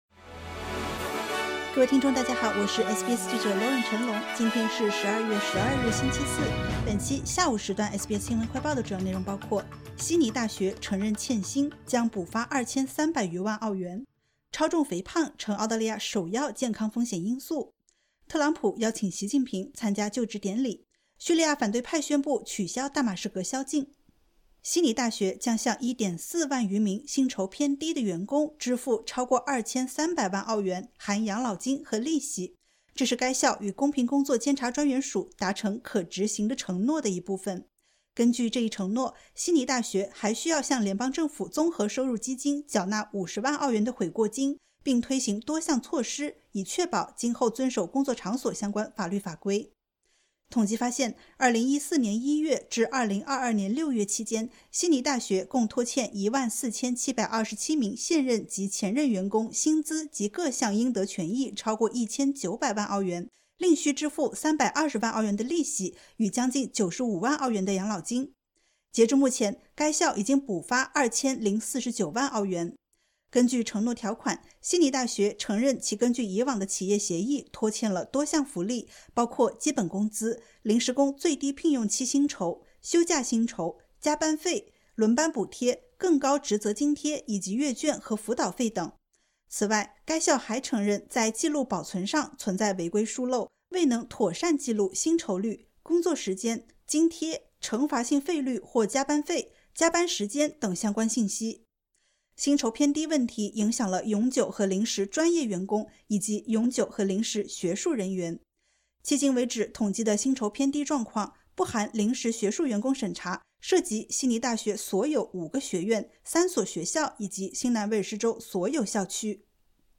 【SBS新闻快报】悉尼大学承认欠薪 将补发2300余万澳元